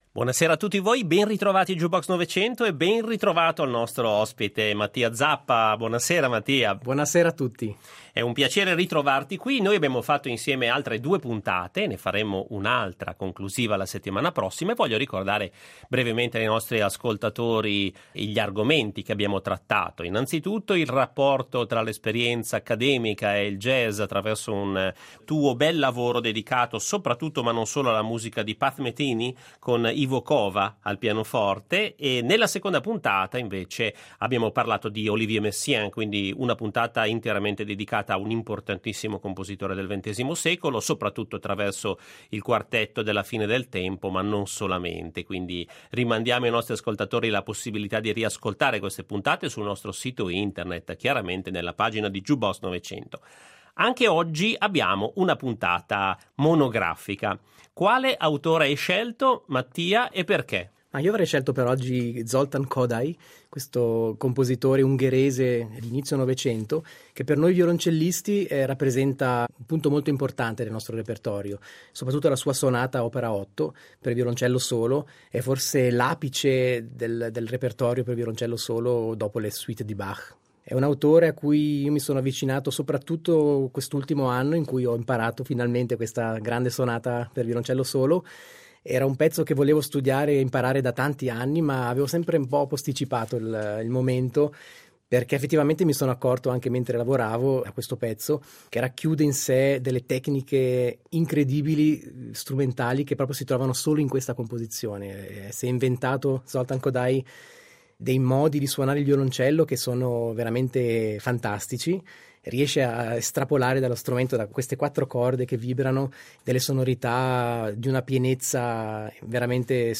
assieme al violoncellista ticinese